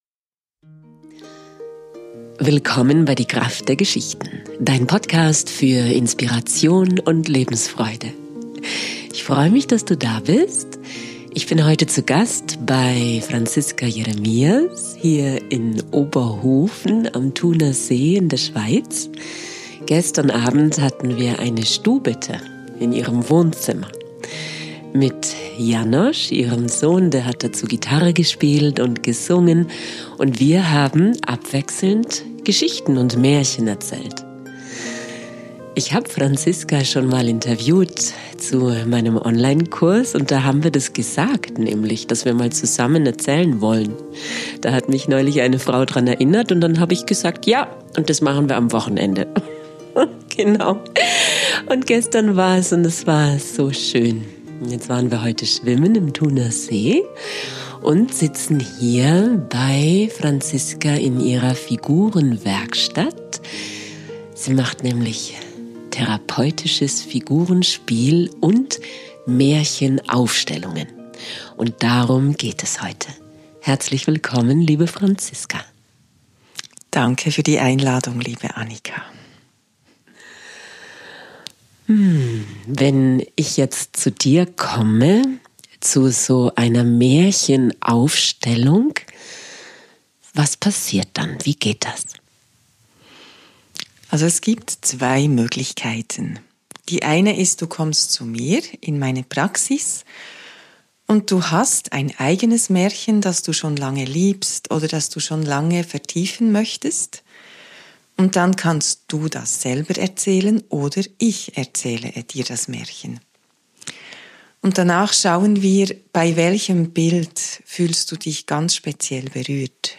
Lass Dich inspirieren von dem heutigen Interview und fühl dich von Herzen u...